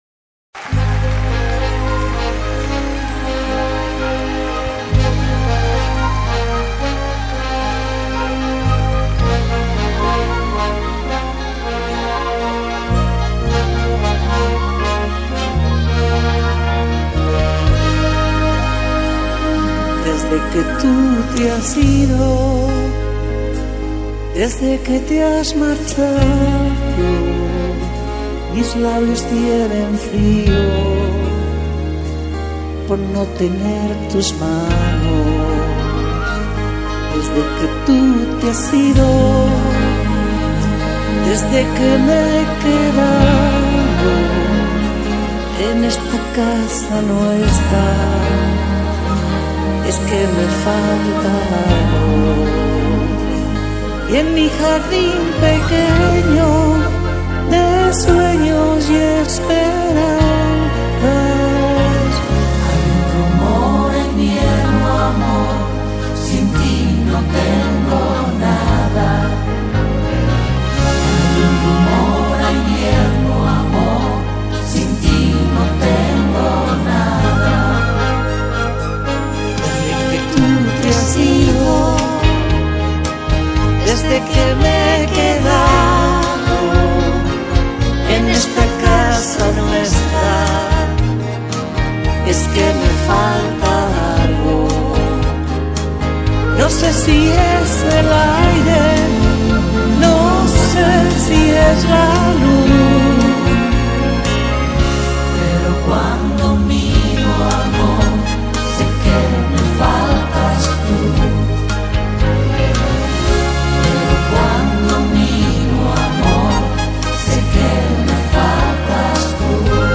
Carpeta: Lentos en español mp3
Sinfonico En Vivo